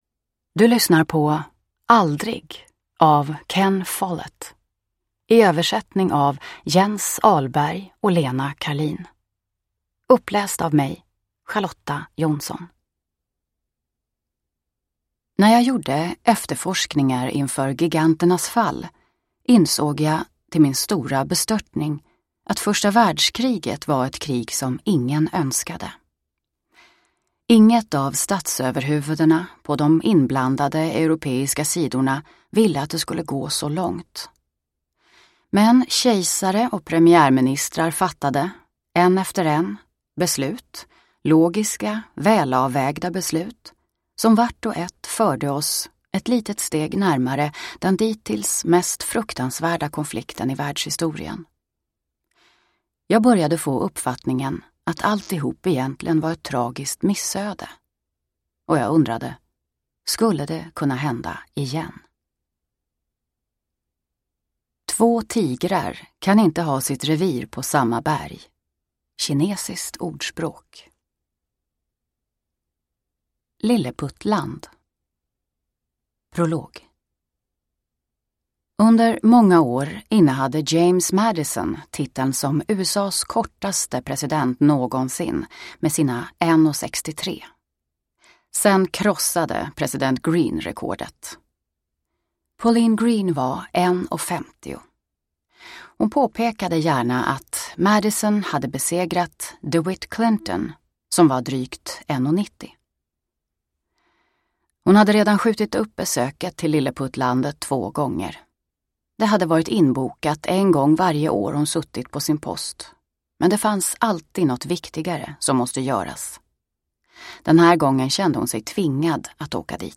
Aldrig – Ljudbok – Laddas ner